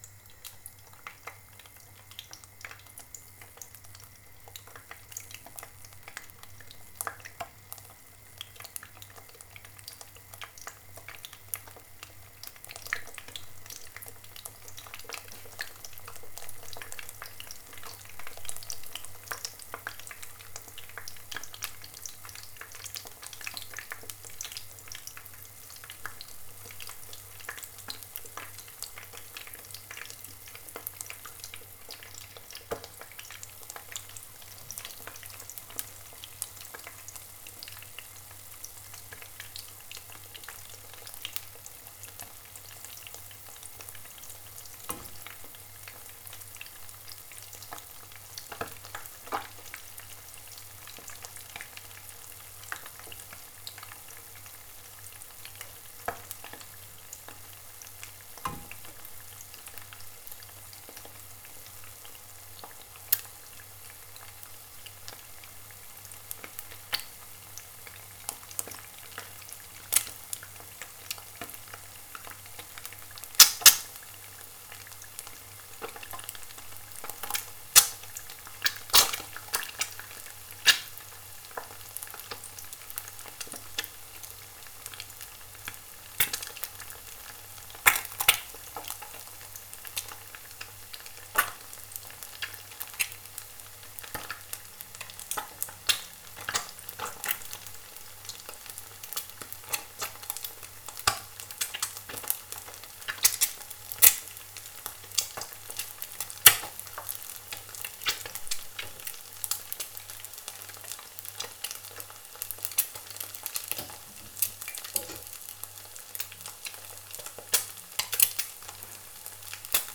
• frying an egg sound.wav
frying_an_egg_sound_l2m_pKT.wav